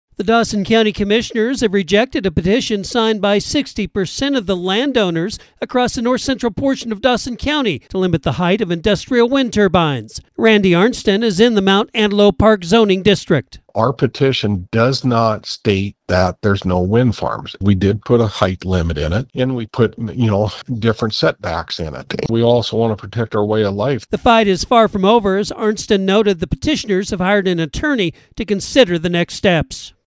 files this report.